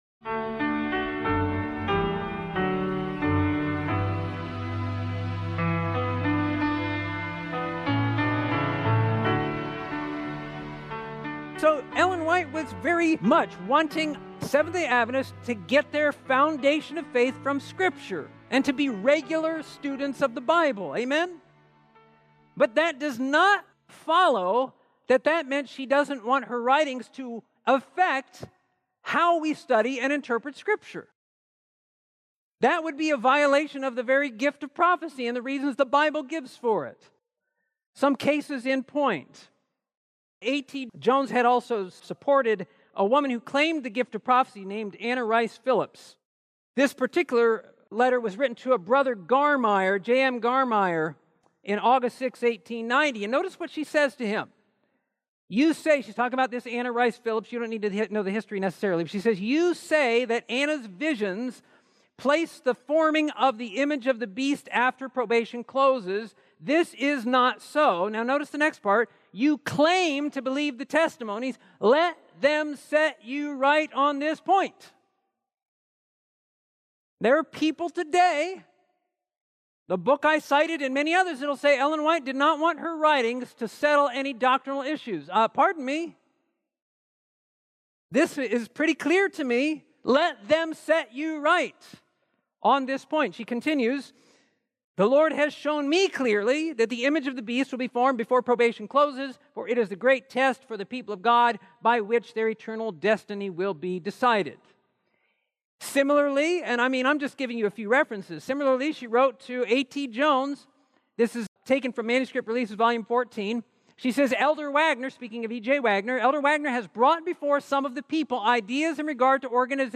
This sermon explores the vital role of Ellen White’s prophetic ministry as a clarifying voice alongside Scripture, emphasizing her contributions to church doctrine, governance, and spiritual growth. Through historical insight and theological balance, it highlights the ongoing relevance of prophecy in nurturing a faithful, Spirit-led community.